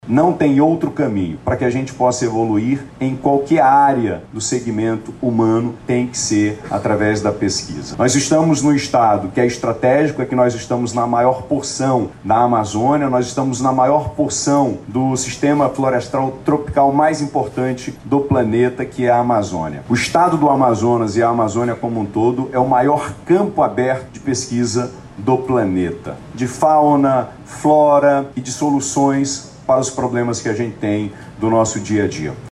Nesta quinta-feira, 18/04, foi realizada a abertura da 5ª Conferência Regional Norte de Ciência, Tecnologia e Inovação, no auditório da academia STEM da Escola Superior de Tecnologia da Universidade do Estado do Amazonas (EST/UEA).
De acordo com o Governador Wilson Lima, a presença da ministra é uma demonstração que o Governo Federal incentiva a questão da ciência, tecnologia e inovação em uma região estratégica para o planeta.